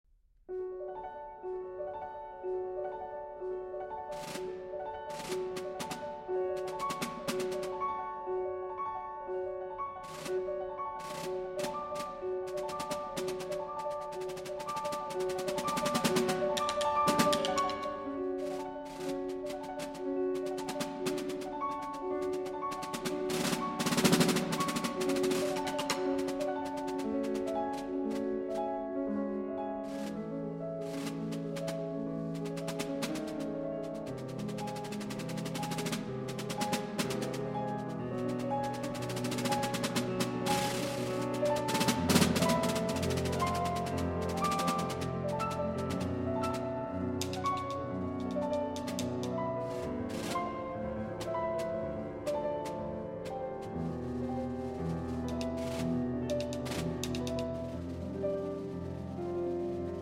Snare Drum
Piano